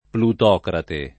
plutocrate [ plut 0 krate ] s. m.